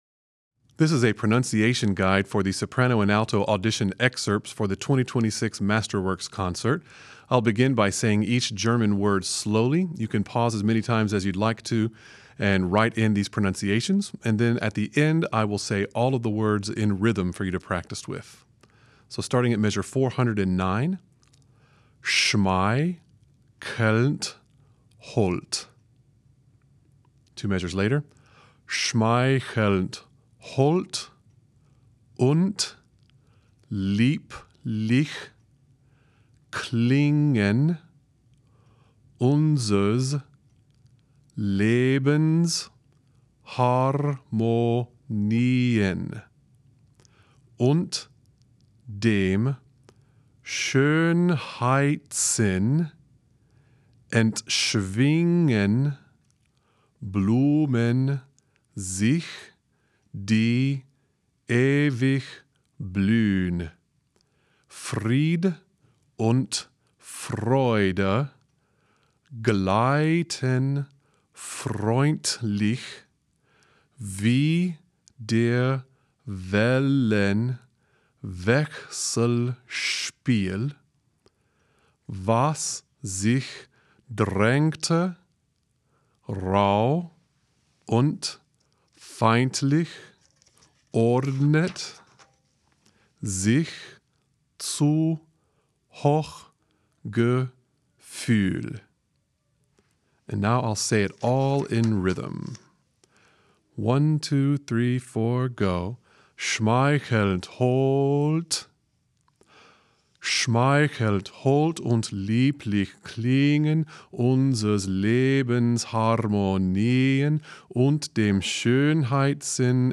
2)  Learn the German pronunciation with this pronunciation guide:
Soprano/Alto Pronunciation Guide